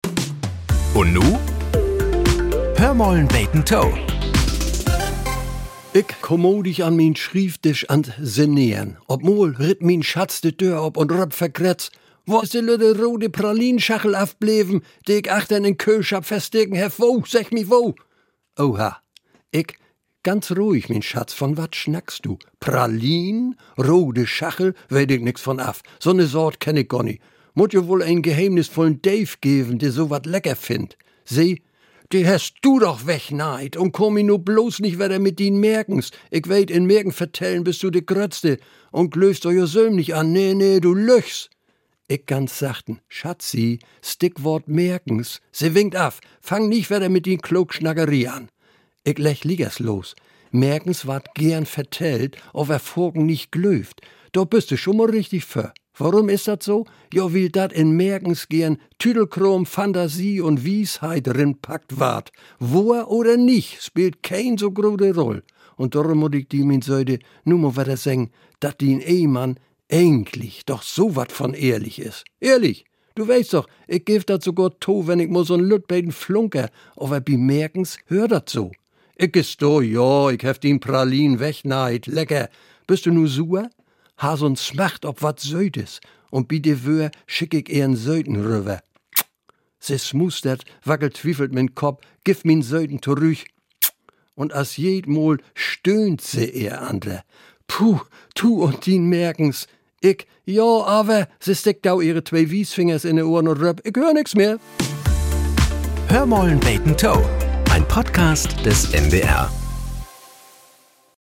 Die plattdeutsche Morgenplauderei "Hör mal 'n beten to" gehört seit mehr als 60 Jahren zum Alltag in Norddeutschland. Hier werden die Wunderlichkeiten des Alltags betrachtet. So klingt es, wenn wir Norddeutschen uns selbst auf die Schippe nehmen - liebevoll bis spöttisch, selten mit dem Finger in schmerzenden Wunden, aber immer an Stellen, an denen wir kitzelig sind.